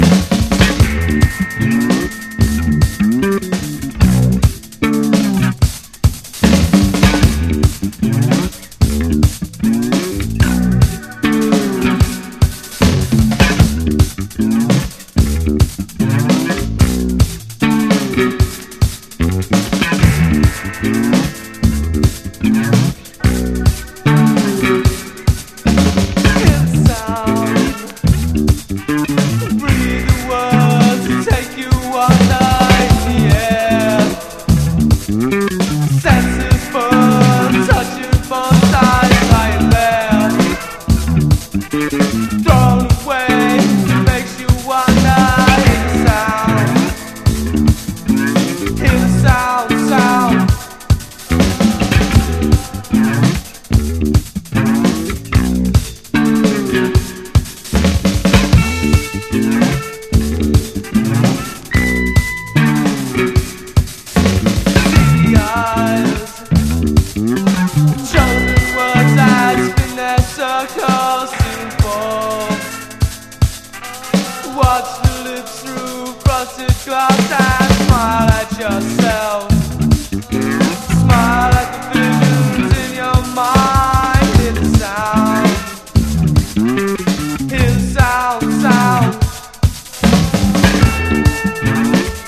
ROCK
NEO-ACO/GUITAR POP / INDIE / NEW WAVE / POST PUNK